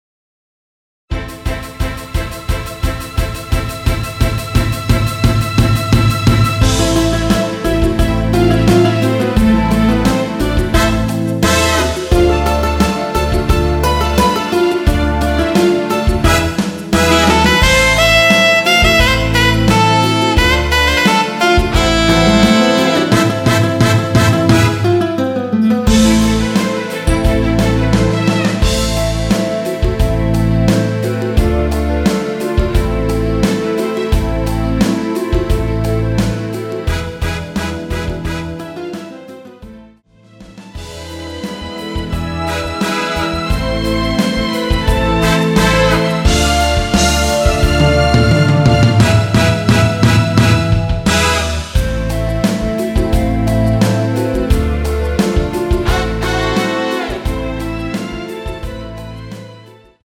원키 멜로디 포함된 MR입니다.
Am
멜로디 MR이라고 합니다.
앞부분30초, 뒷부분30초씩 편집해서 올려 드리고 있습니다.
중간에 음이 끈어지고 다시 나오는 이유는